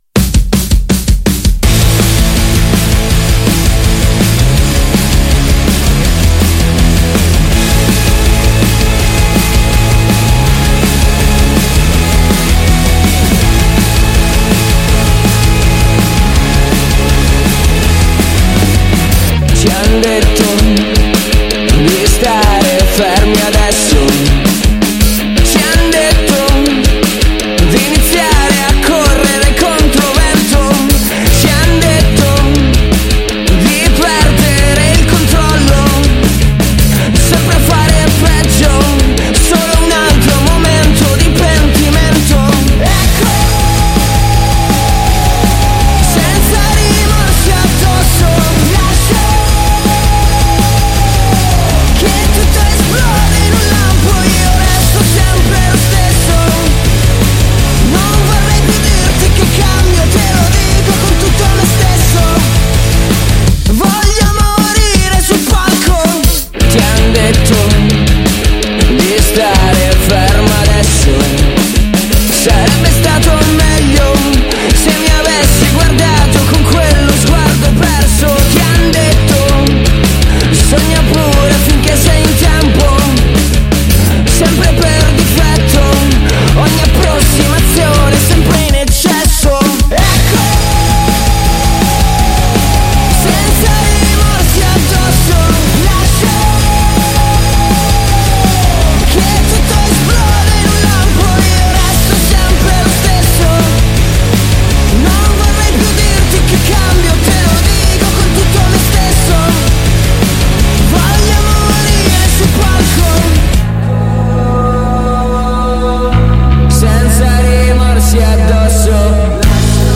Intervista ai Marrano | 3-10-22 | Radio Città Aperta
intervista-marrano-3-10-22.mp3